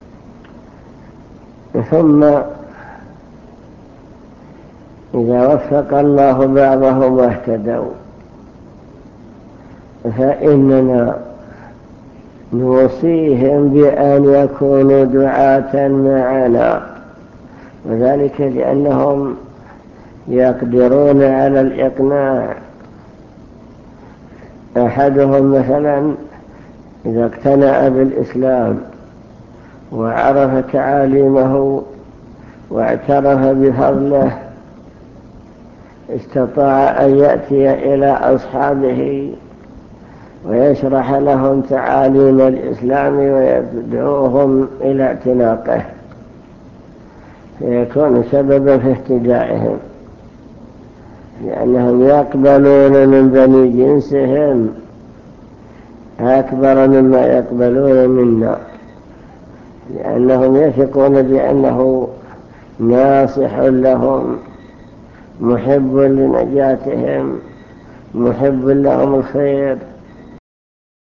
المكتبة الصوتية  تسجيلات - لقاءات  لقاء مع الشيخ بمكتب الجاليات